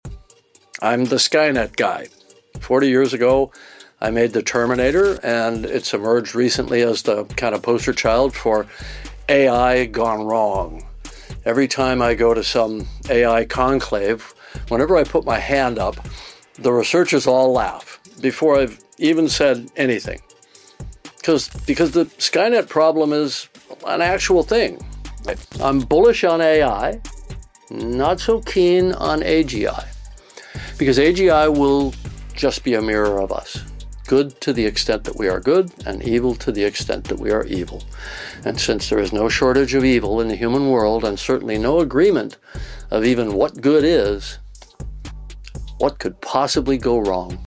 In a special video message at the SCSP AI+Robotics Summit, James Cameron, known for his role as “the Skynet guy” behind The Terminator, shared his perspectives on the real-world implications of advancing AI and robotics.